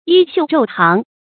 衣繡晝行 注音： ㄧ ㄒㄧㄨˋ ㄓㄡˋ ㄒㄧㄥˊ 讀音讀法： 意思解釋： 晝：白天。